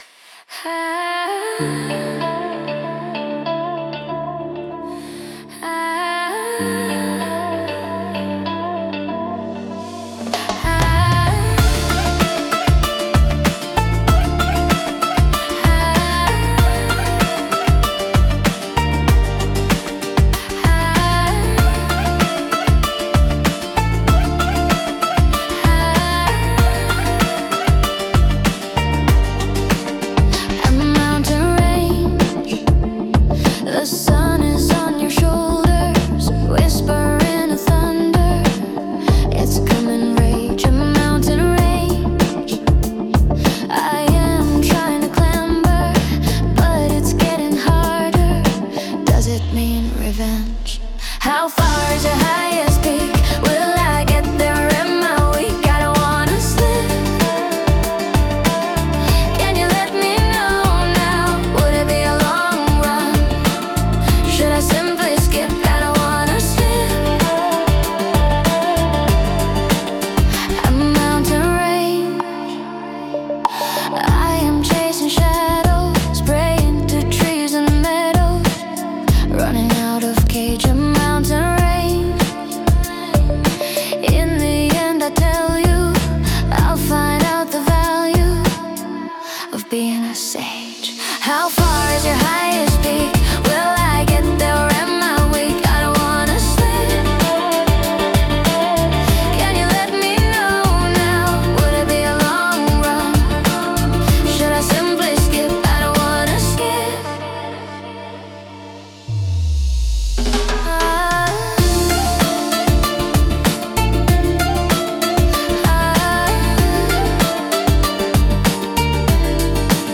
Пример 2: Хип-хоп «кач» с воздухом
хип-хоп, средний темп, качовый грув, глубокий кик, плотный снейр, редкие хэты, акценты в паузах